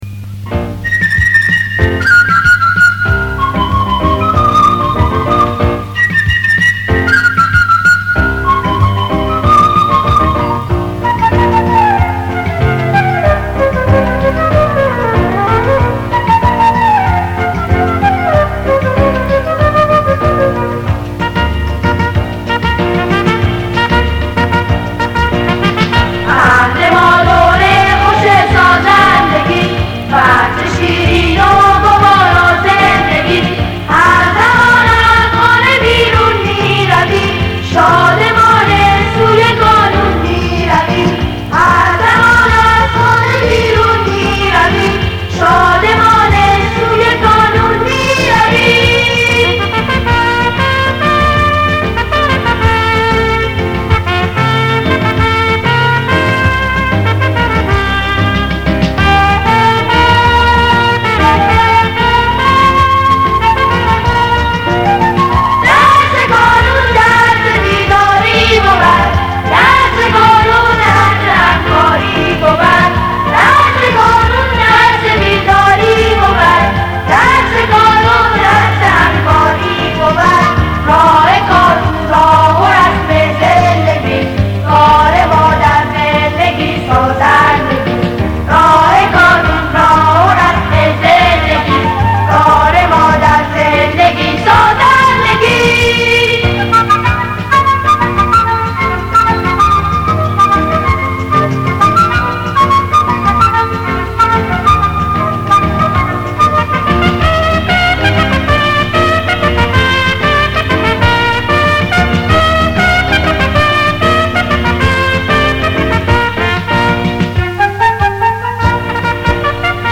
سرود خانه ام کانون